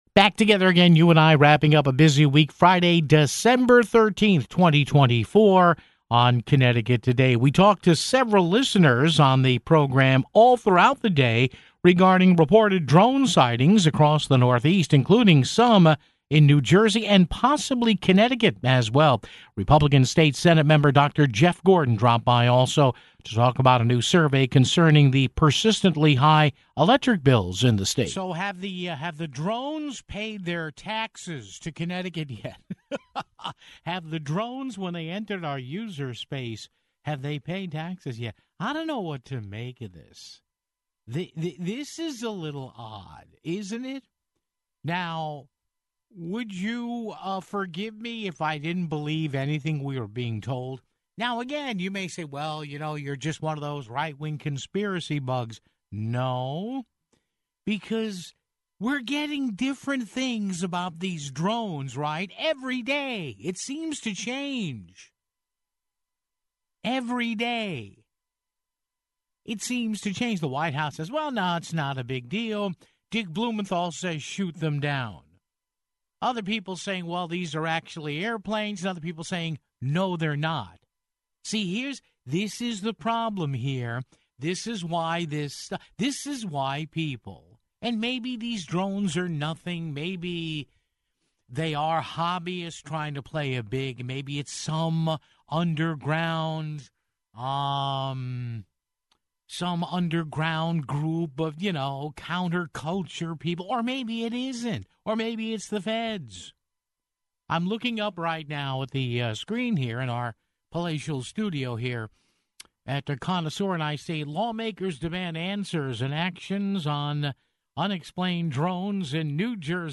talked to several listeners on Friday's "Connecticut Today" regarding reported drone sightings across the Northeast, including in New Jersey and - possibly - in Connecticut (00:28). GOP State Sen. Dr. Jeff Gordon dropped by to talk about a new survey concerning Connecticut electric bills (14:34)